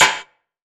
SNARE - GIVER.wav